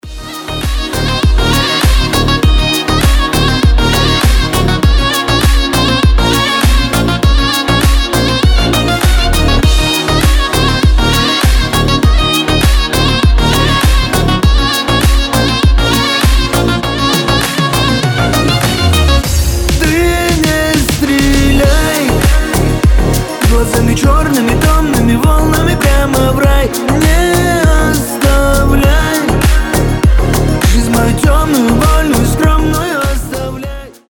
• Качество: 320, Stereo
восточные